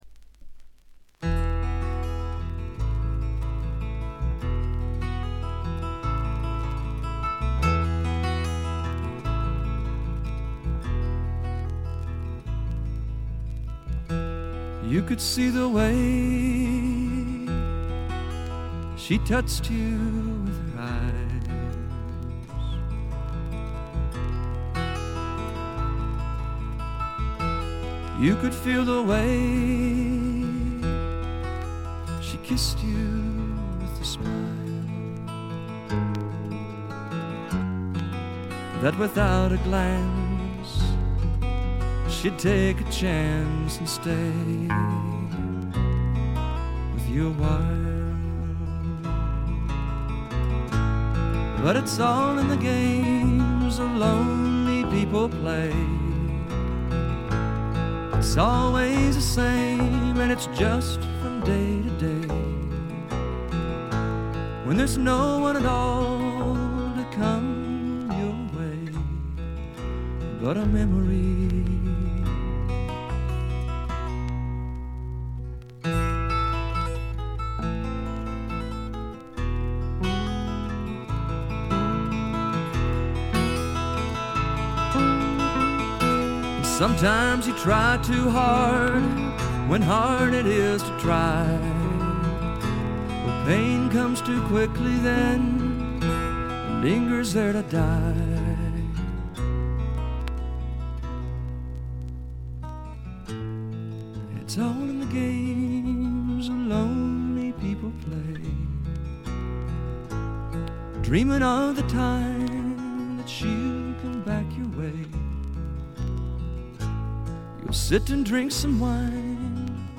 ところどころでバックグラウンドノイズ、チリプチ。
試聴曲は現品からの取り込み音源です。
Lead Vocals, Acoustic Guitar